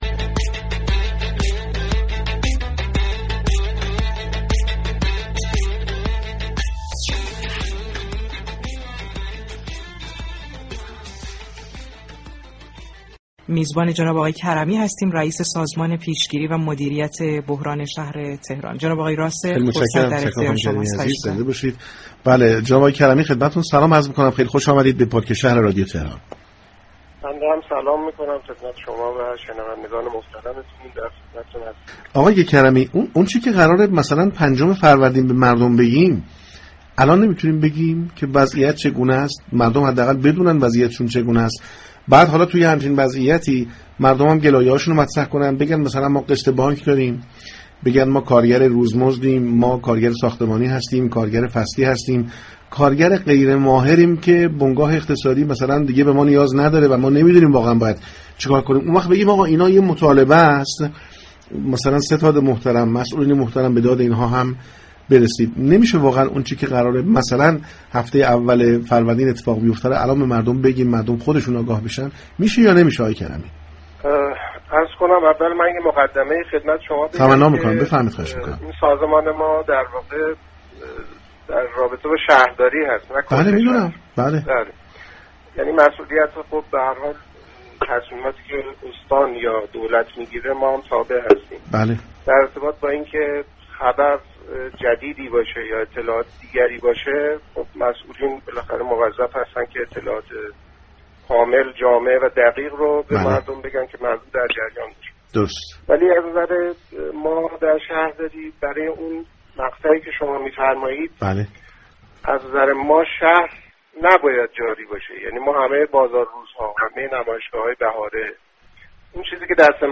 رضا كرمی رئیس سازمان پیشگیری و مدیریت بحران شهر تهران، در گفتگو با برنامه پارك شهر چهارشنبه 21 اسفندماه اعلام كرد كه از نظر شهرداری هفته اول فروردین، شهر باید تعطیل باشد.